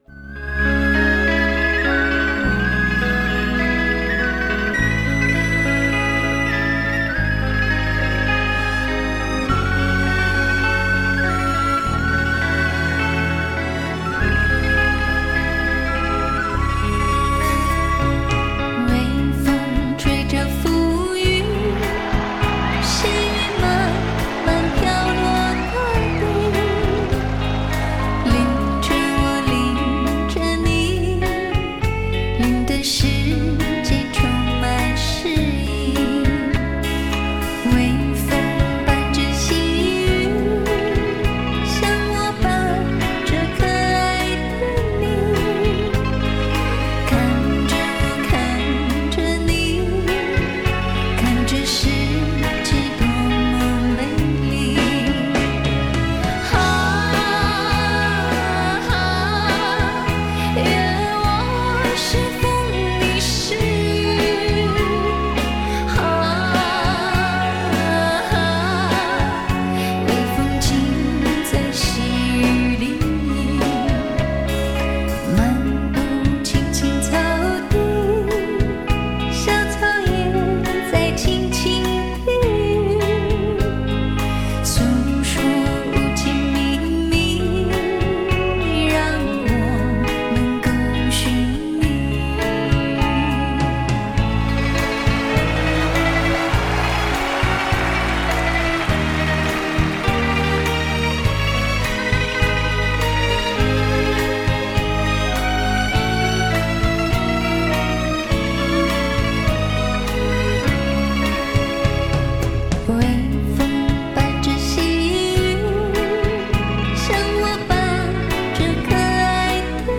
；背景音乐